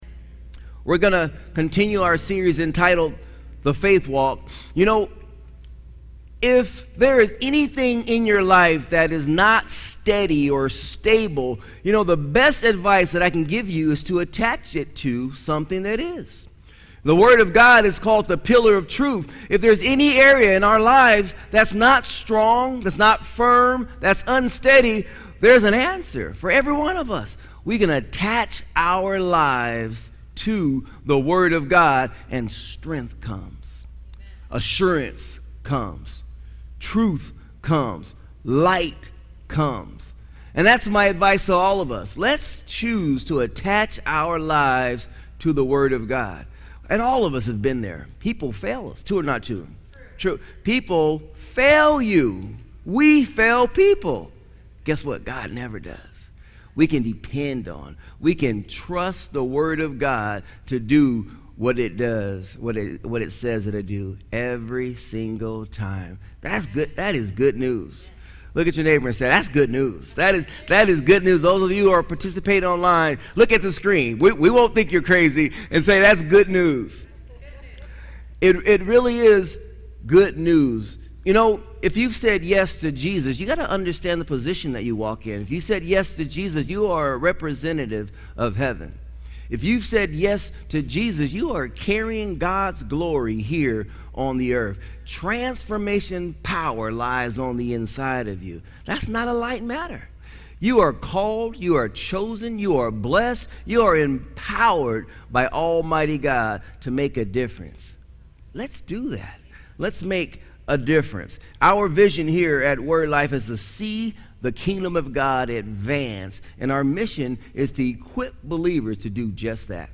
Sermons | Word Life Church